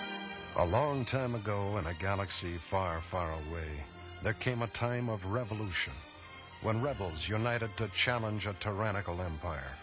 ―Introduction to each installment of the radio drama — (audio)